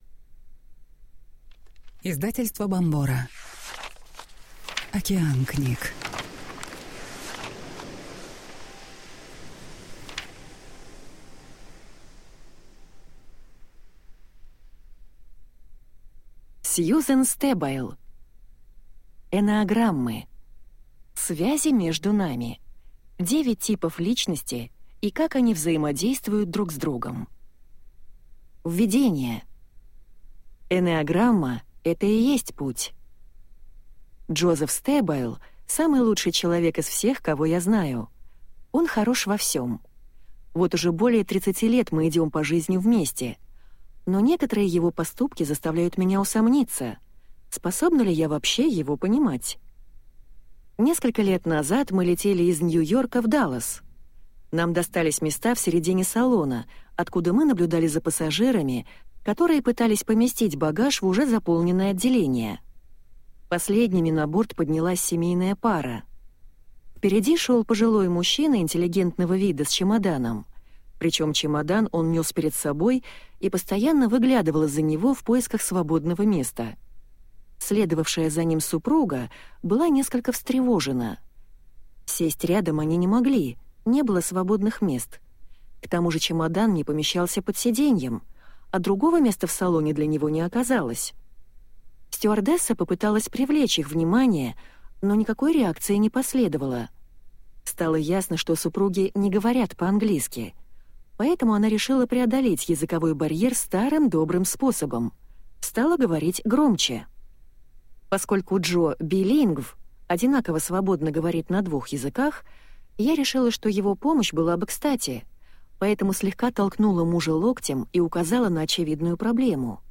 Аудиокнига Связи между нами. 9 типов личности и как они взаимодействуют друг с другом | Библиотека аудиокниг